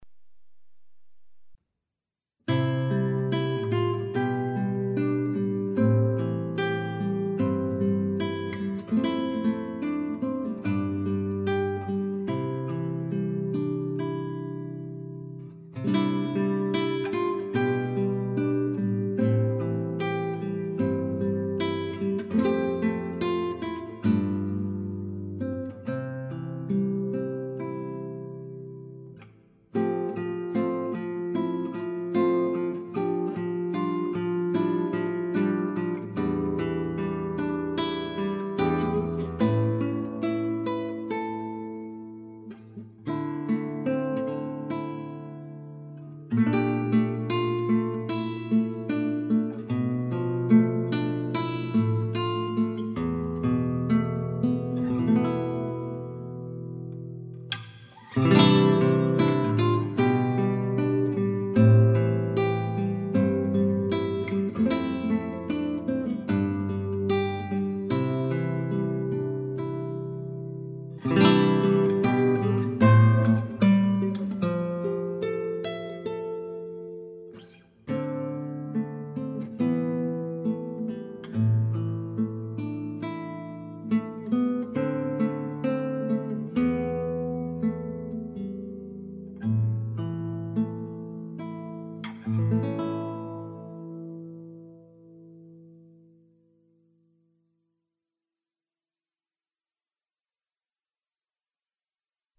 0002-吉他名曲积极的少女心.mp3